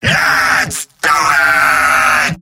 Robot-filtered lines from MvM. This is an audio clip from the game Team Fortress 2 .
Demoman_mvm_battlecry05.mp3